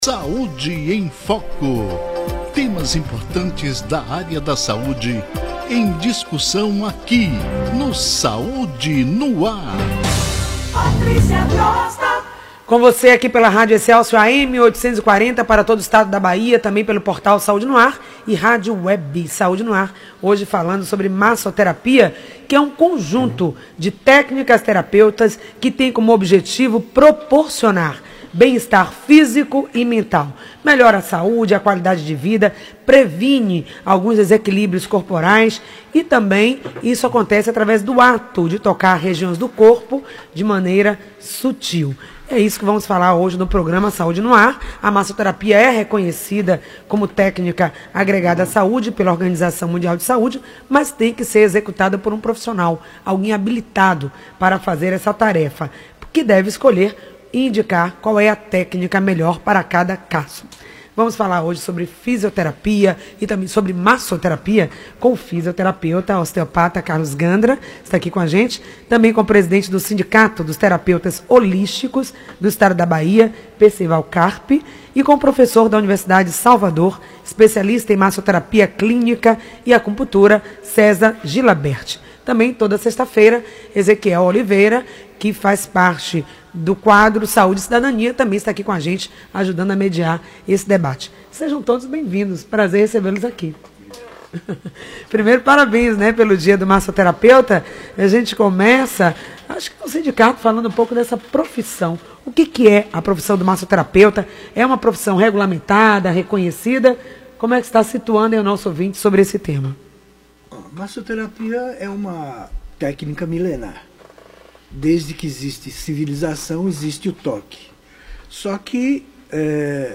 Ouça a entrevista na íntegra ou assista ao vídeo no Facebook